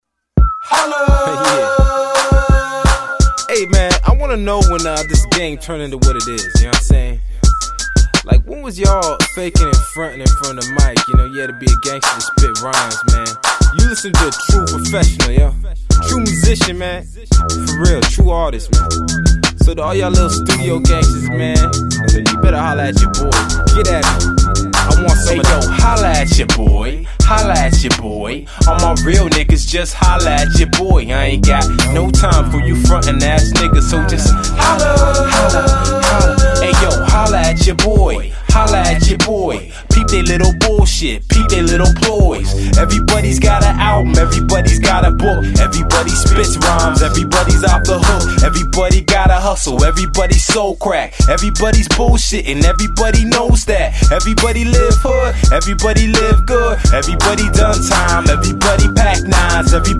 Genre: Rap & Hip-Hop.